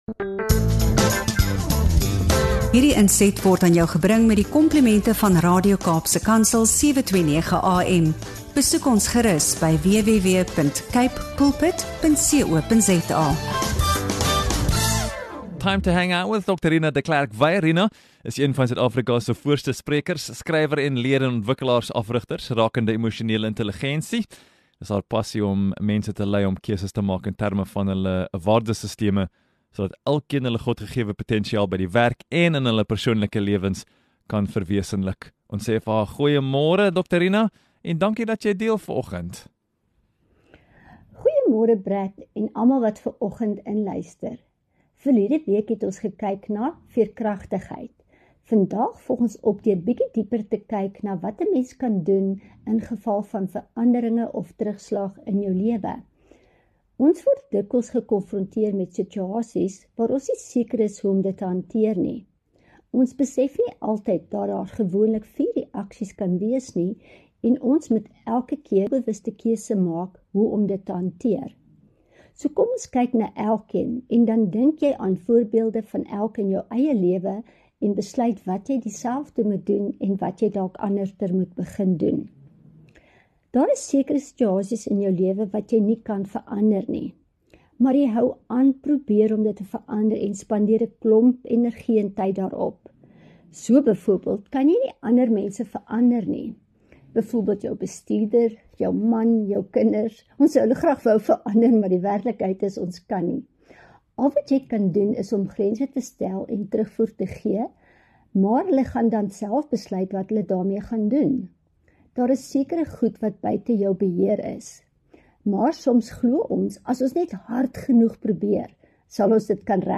GET UP & GO BREAKFAST - INTERVIEW SPECIALS